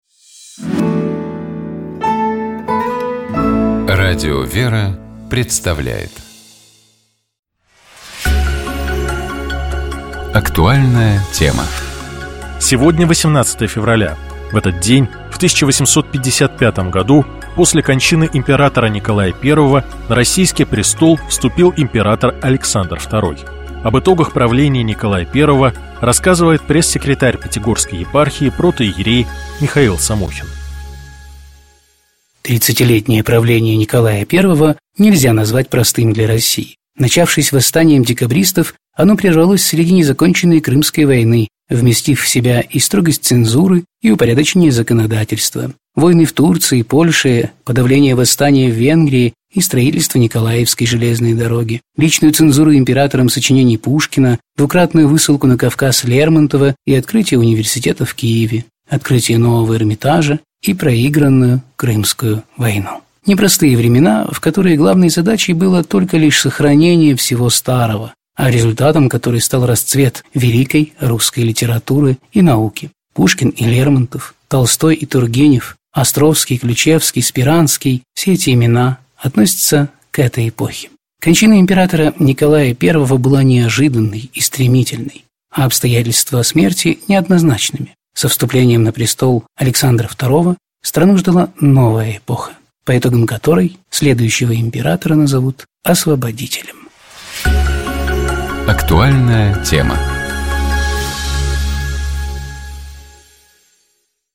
Каждый день мы выбираем самые насущные темы и приглашаем гостей рассуждать вместе с нами.